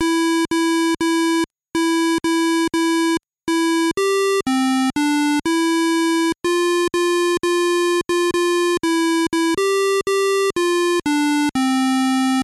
In 1983 someone wrote a program for a version of BASIC used on IBM PC and compatible computers. This program could play ten Christmas songs through the built-in PC speaker using the Play statement. While this just allows a single note at a time, if programmed correctly, it can still sound good…but this particular version of Jingle Bells was not programmed correctly, to say the least!